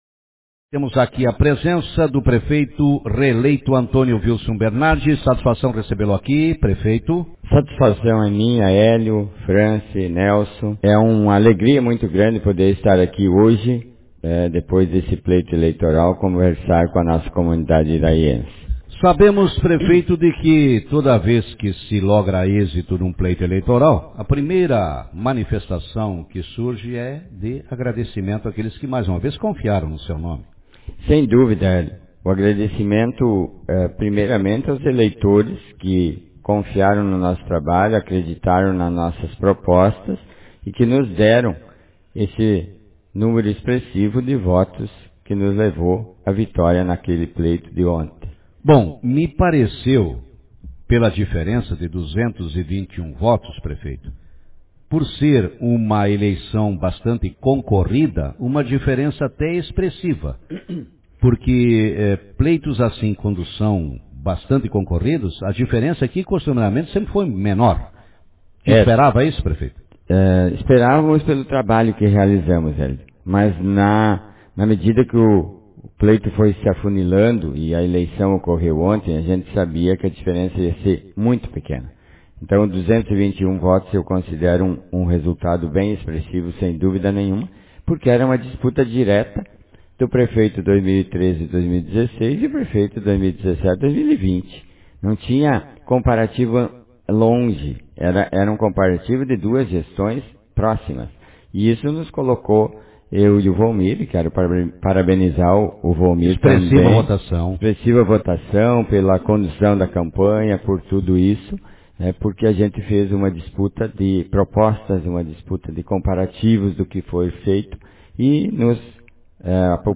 Prefeito reeleito de Iraí agradece a votação recebida no pleito de ontem Autor: Rádio Marabá 16/11/2020 0 Comentários Manchete O prefeito Antonio Vilson Bernardi, reeleito ontem em Iraí, falou no programa Café com Notícias e disse que dará continuidade ao seu trabalho frente ao Executivo municipal.